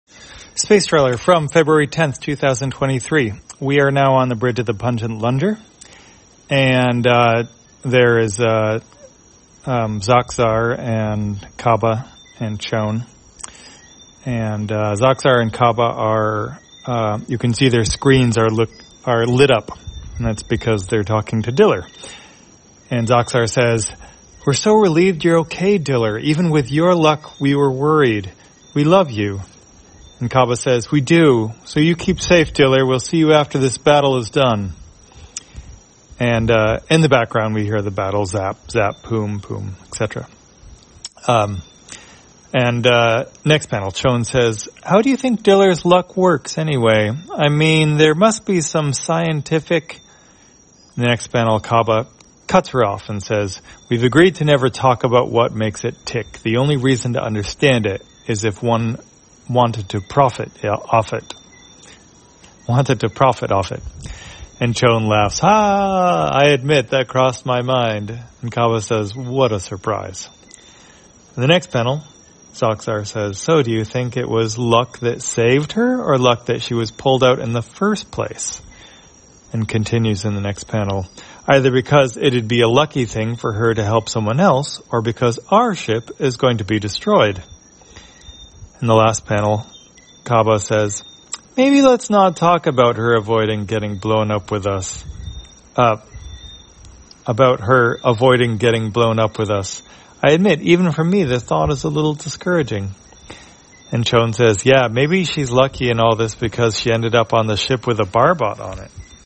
Spacetrawler, audio version For the blind or visually impaired, February 10, 2023.